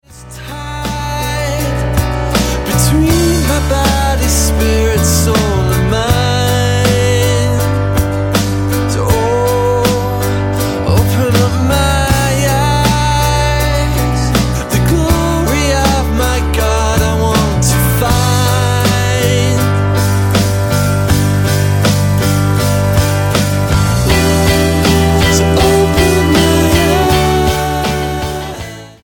Amblecote Indie Rock Band
Style: Pop